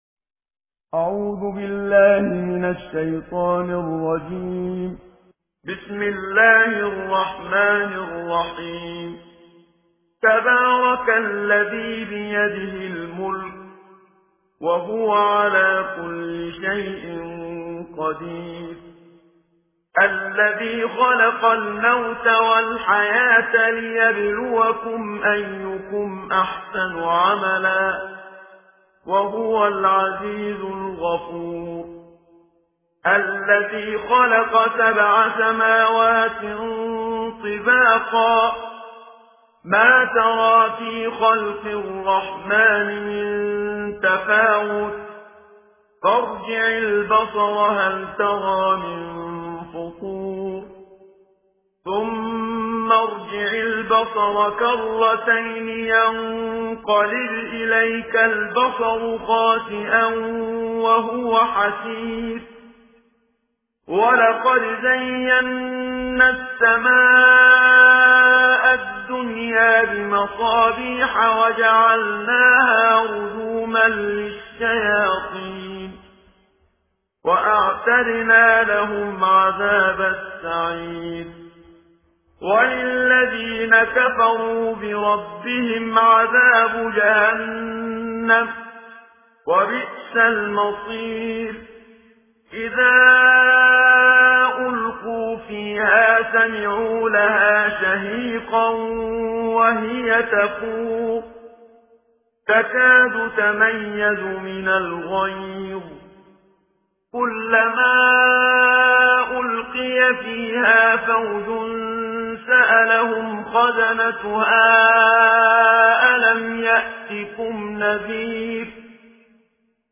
صوت/ ترتیل جزء بیست‌ونهم قرآن توسط "منشاوی"